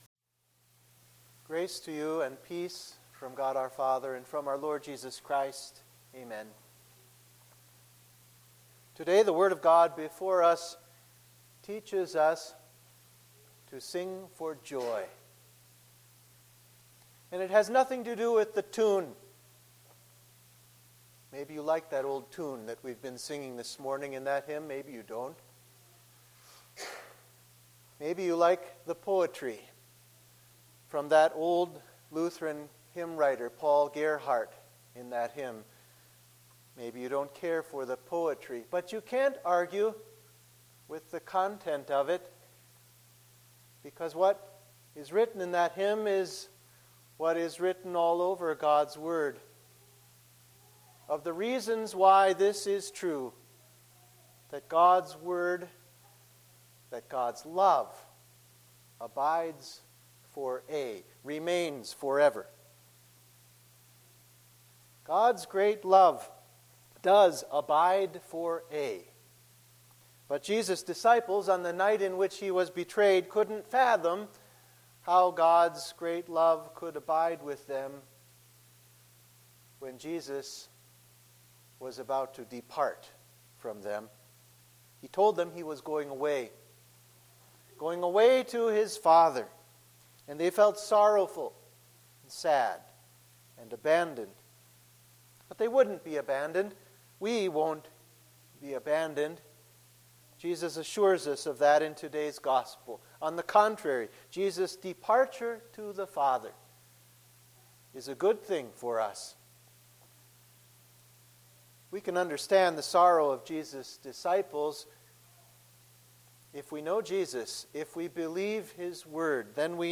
Sermon for Cantate – Fourth Sunday after Easter